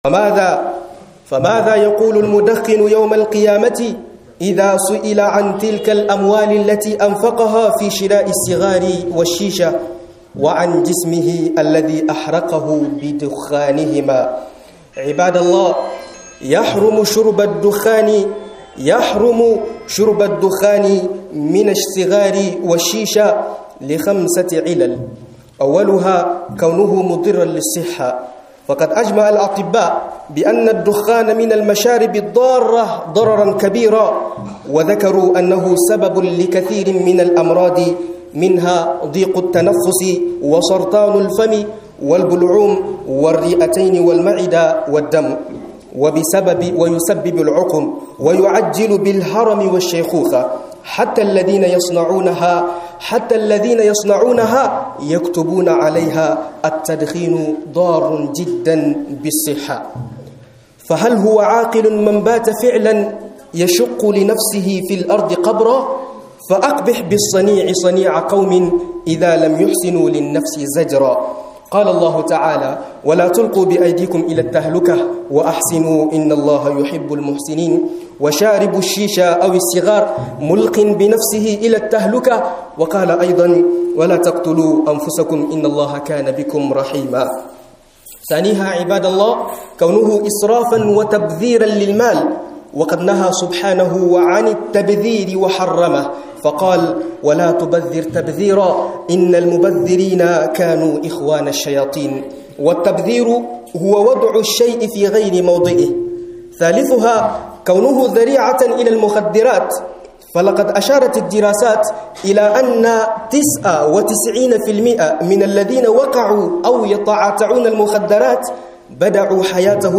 Jan Kunnay Gameda Chicha da sigari - MUHADARA